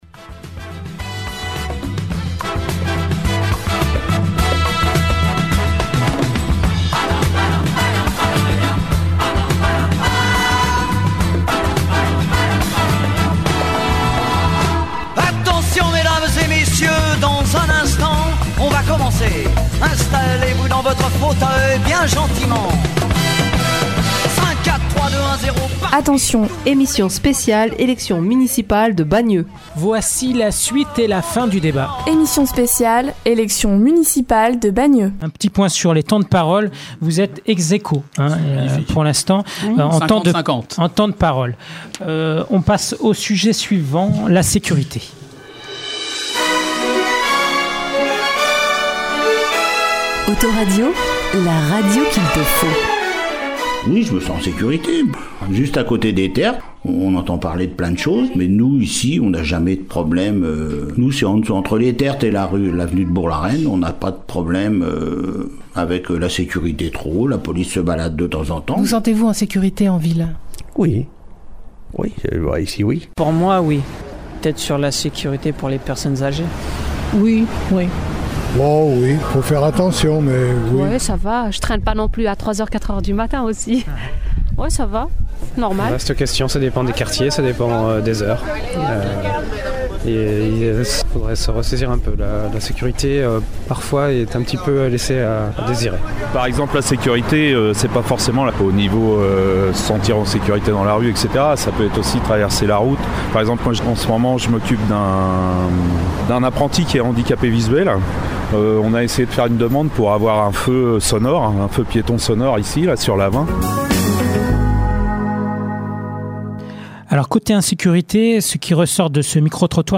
Emission politique : le débat des élections municipales de Bagneux
debat-bagneux-elections-municipales-2014-partie2.mp3